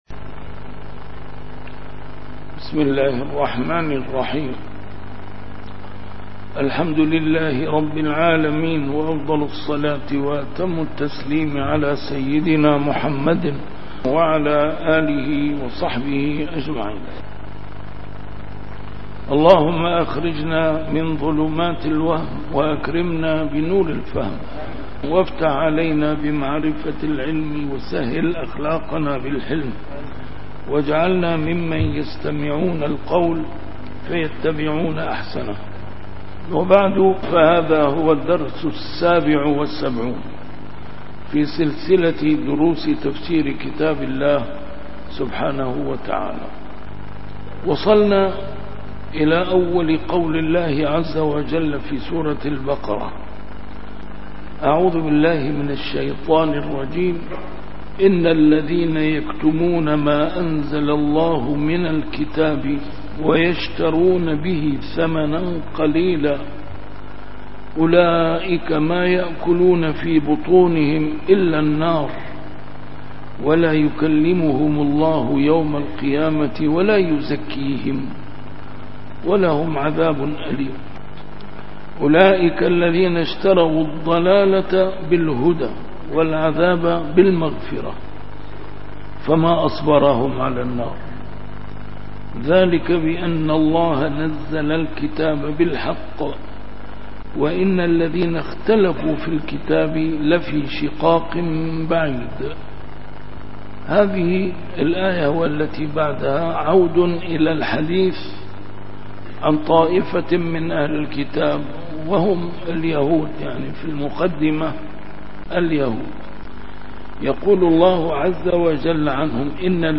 A MARTYR SCHOLAR: IMAM MUHAMMAD SAEED RAMADAN AL-BOUTI - الدروس العلمية - تفسير القرآن الكريم - تفسير القرآن الكريم / الدرس السابع والسبعون: سورة البقرة: الآية 174-176